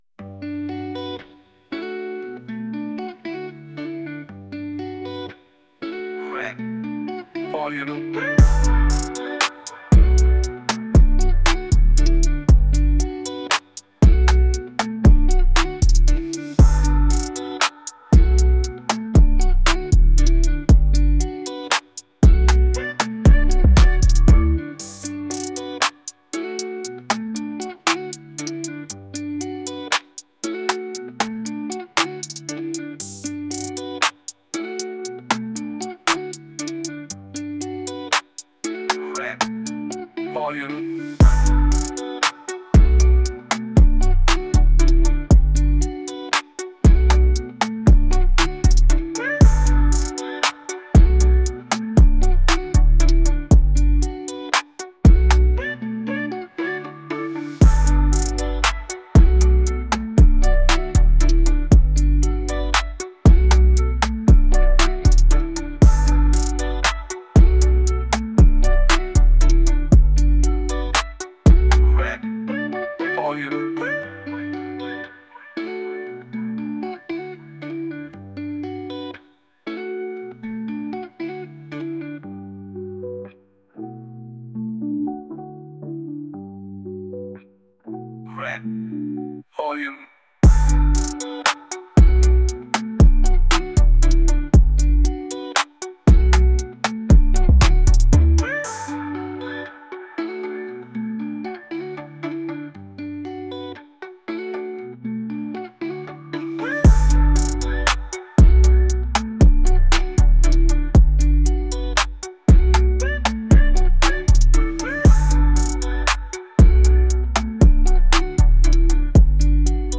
Rap，押韵